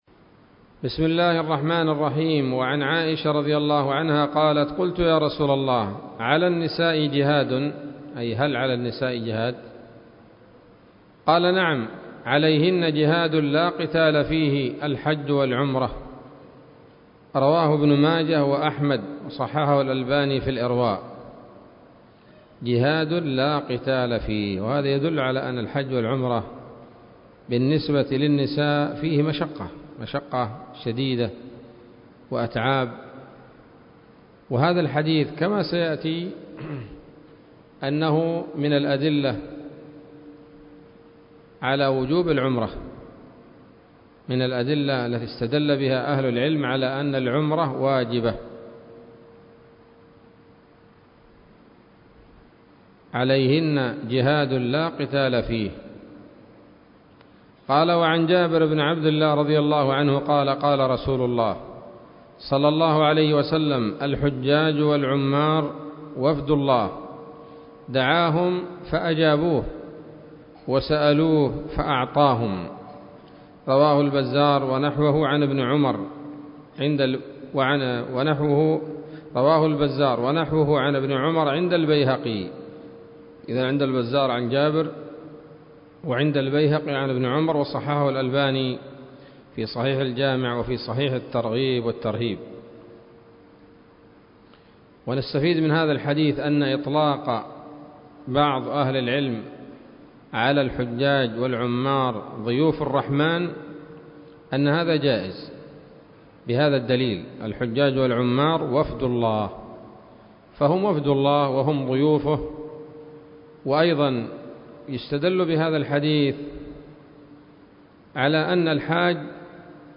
الدرس الثالث من شرح القول الأنيق في حج بيت الله العتيق